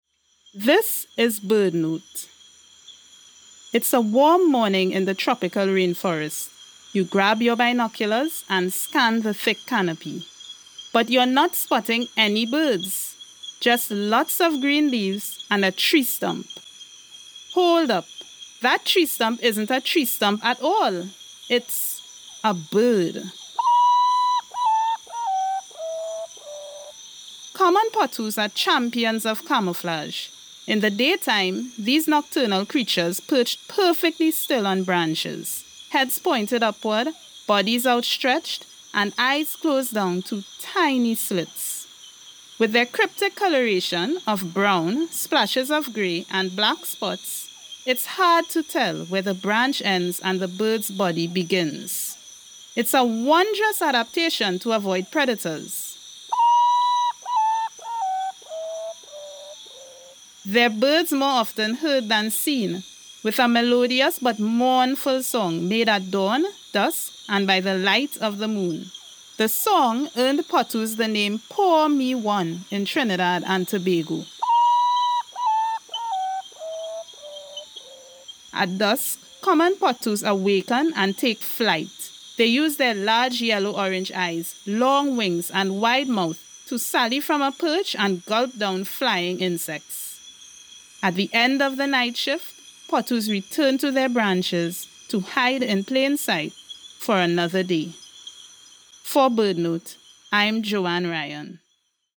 They’re birds more often heard than seen, with a melodious but mournful song, made at dawn, dusk, and by the light of the moon.